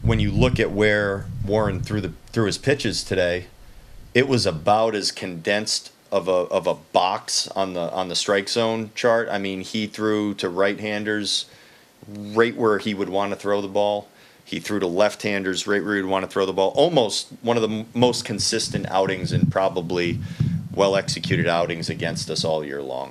Twins manager Rocco Baldelli says Yankees starter and winner Will Warren was good.